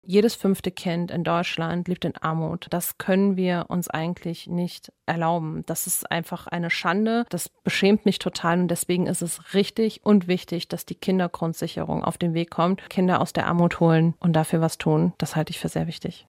Sommerinterview mit Laura Kraft
Unsere Bundestagsabgeordnete war zu Gast bei Radio Siegen.